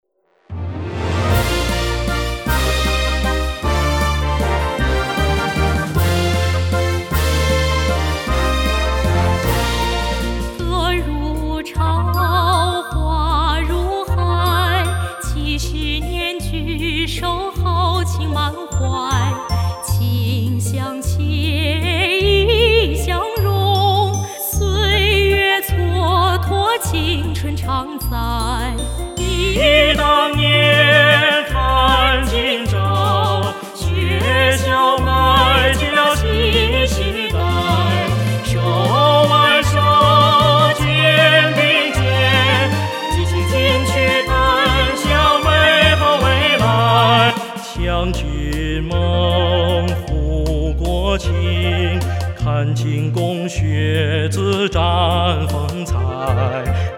合唱团六个声部的混声合唱的前后进入